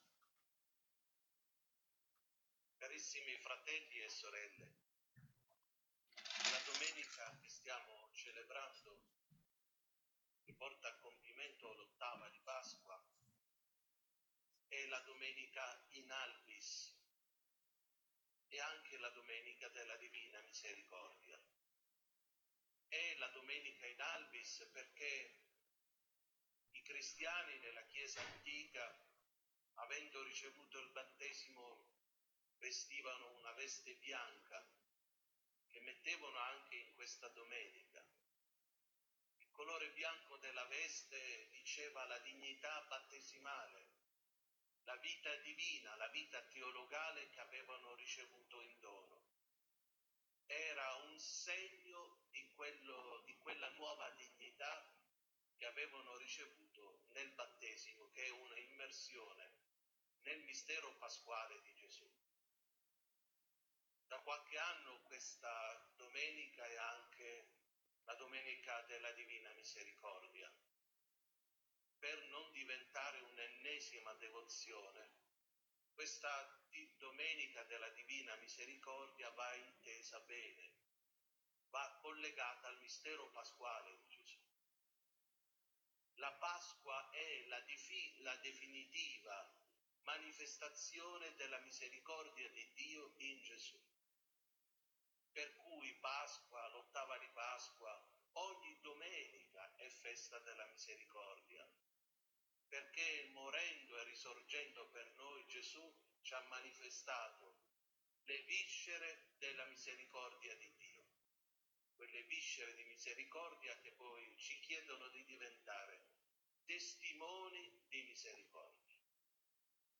01- OMELIA NELLA DOMENICA IN ALBIS - Arcidiocesi di Crotone-Santa Severina
LA S. MESSA CELEBRATA DALL'ARCIVESCOVO
OMELIA DELLA DOMENICA IN ALBIS.mp3